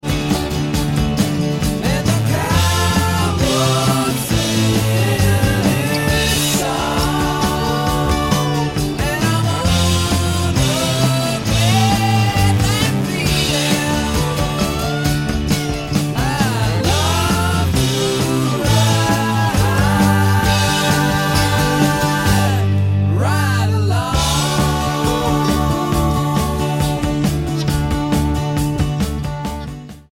guitar, piano, vocals
flute, bass, organ, saxophone, vocals